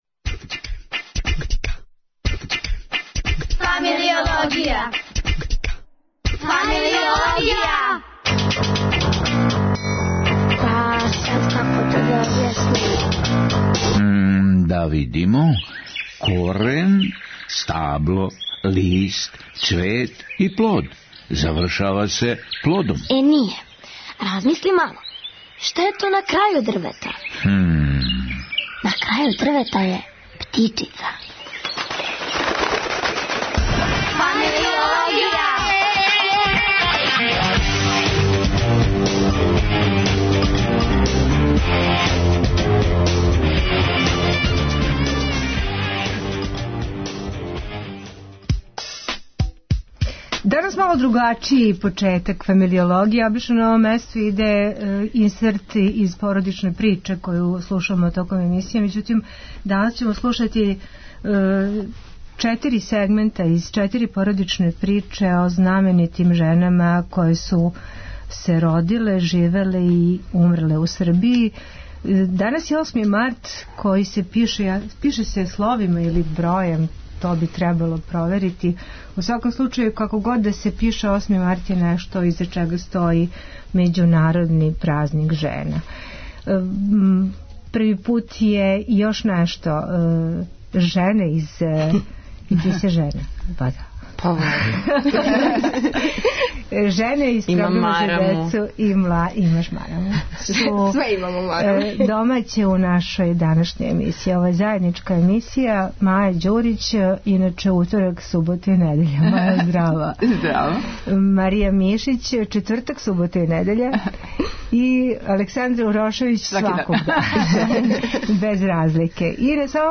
Све жене наше редакције у једној емисији...